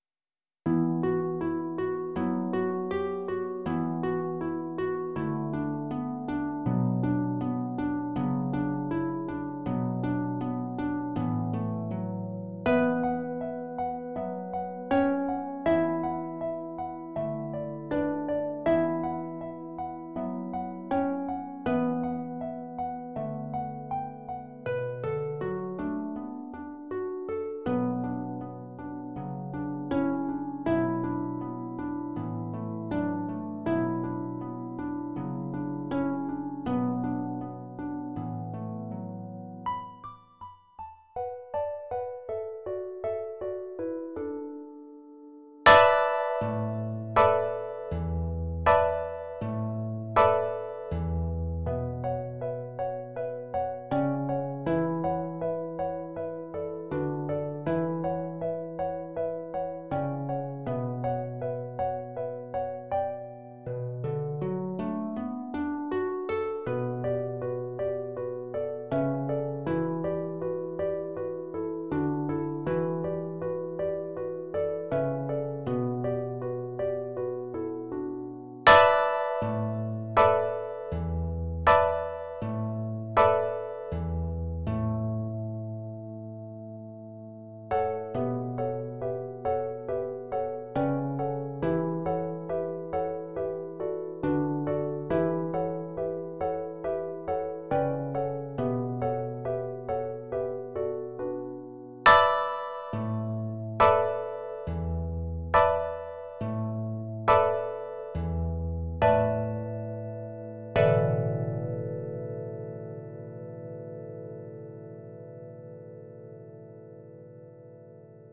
for two lever or pedal harps